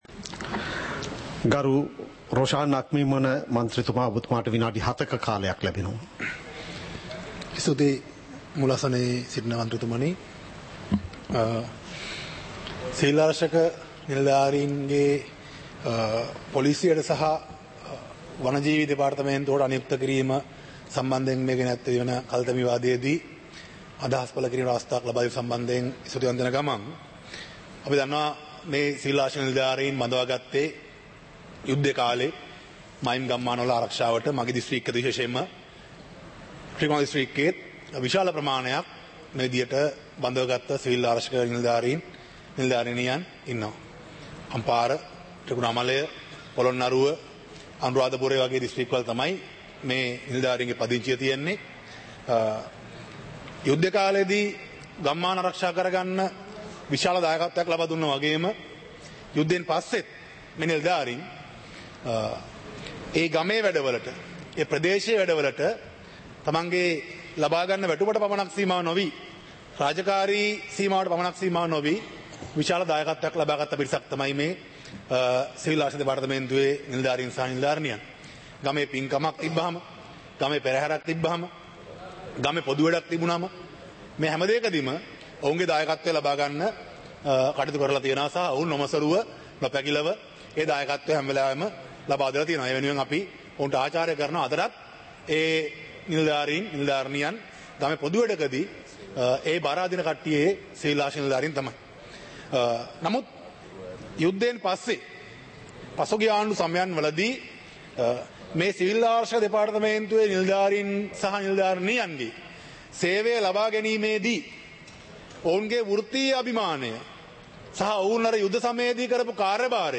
இலங்கை பாராளுமன்றம் - சபை நடவடிக்கைமுறை (2026-03-03)